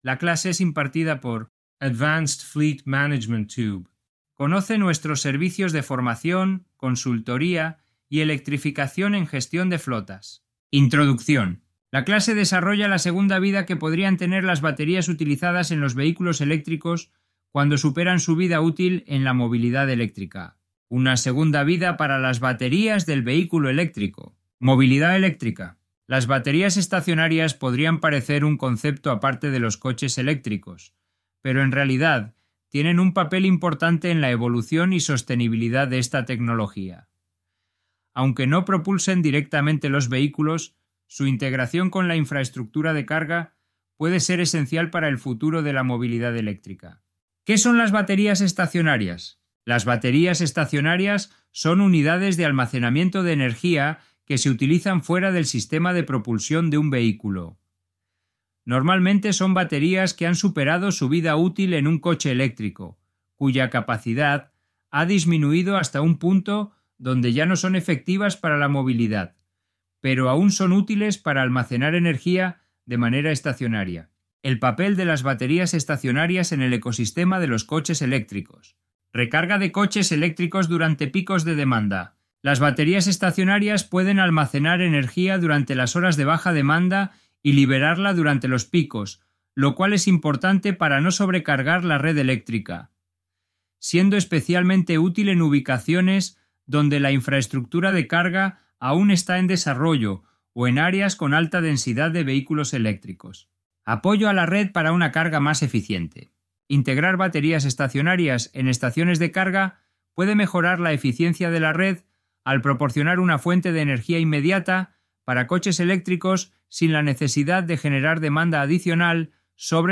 La clase ha desarrollado la segunda vida que podrían tener  las baterías utilizadas en los vehículos eléctricos cuando superan su vida útil en la movilidad eléctrica.